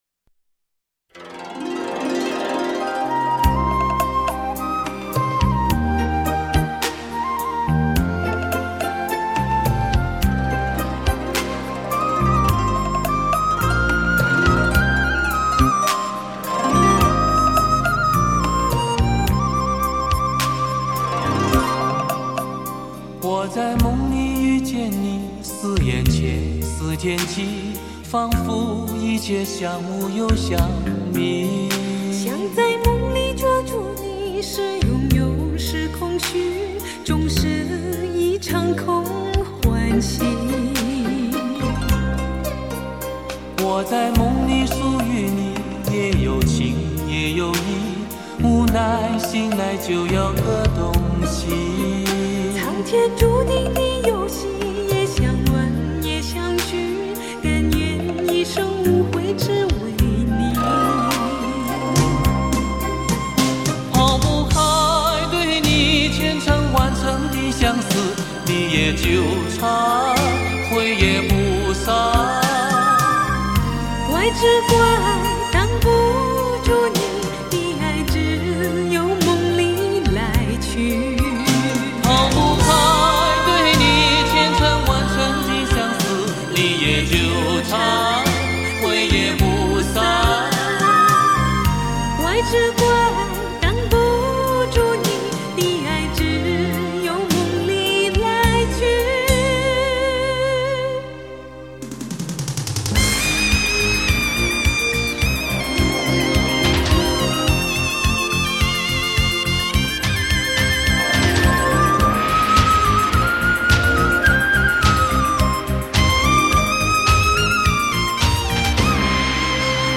婉转动人 韵味十足
天籁般的嗓音柔美动听 仿佛身临其境
不可抗拒的磁性女声
高密度24BIT数码录音